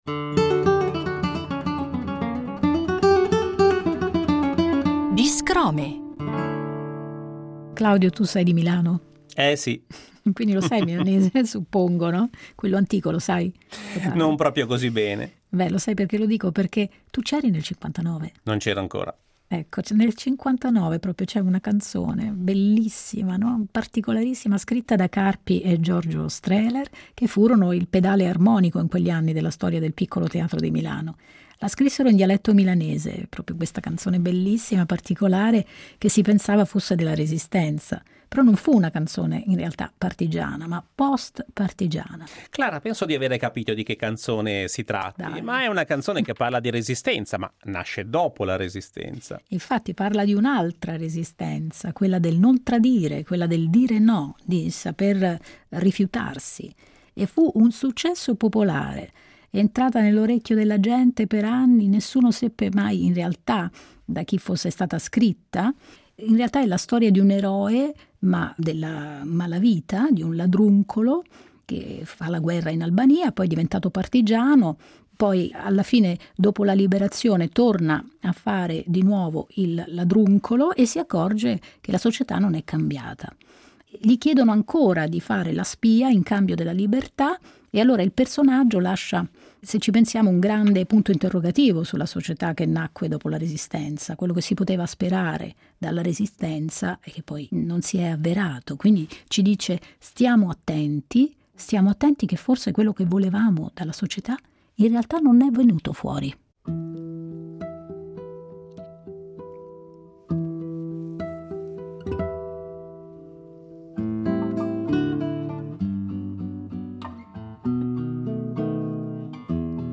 chitarrista